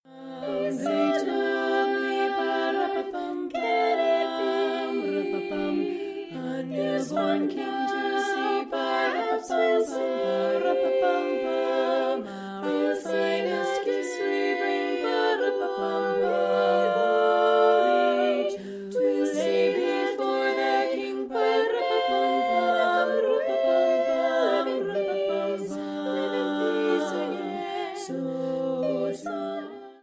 available for either 4 or 5 voice parts